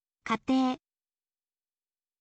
katei